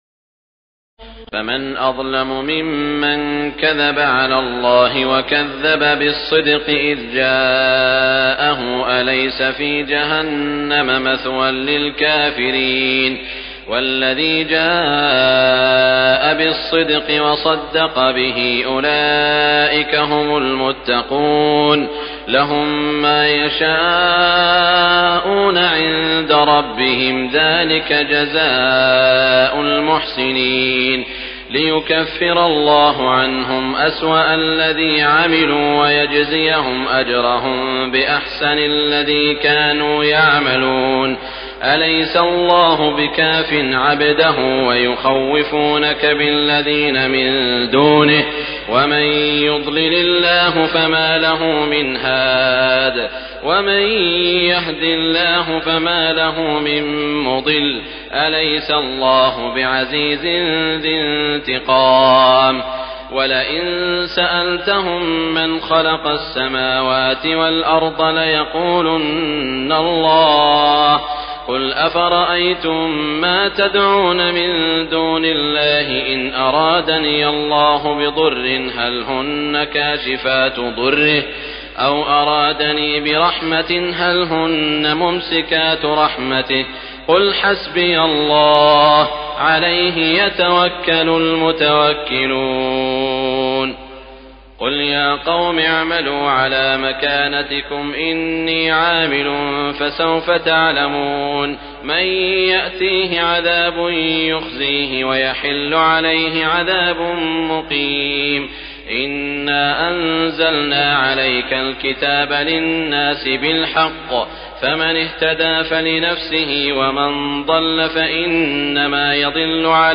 تراويح ليلة 23 رمضان 1418هـ من سور الزمر (32-75) و غافر (1-40) Taraweeh 23 st night Ramadan 1418H from Surah Az-Zumar and Ghaafir > تراويح الحرم المكي عام 1418 🕋 > التراويح - تلاوات الحرمين